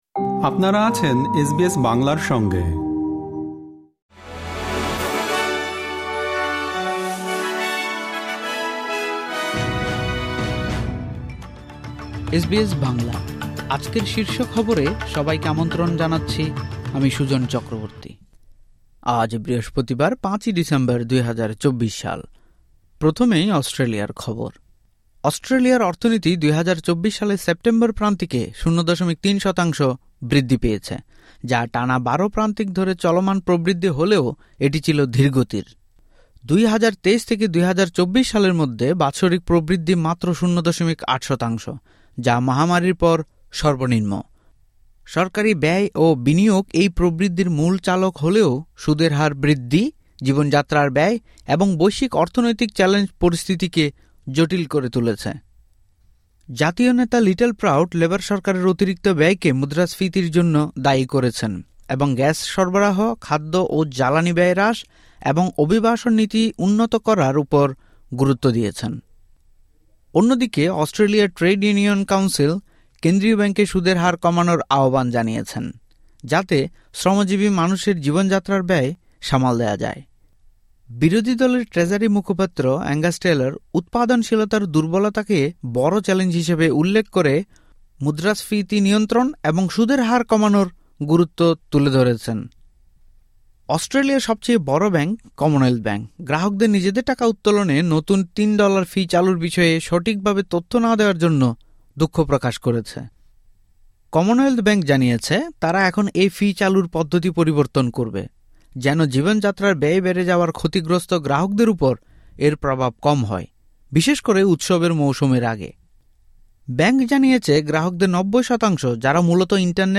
এসবিএস বাংলা শীর্ষ খবর: ৫ ডিসেম্বর, ২০২৪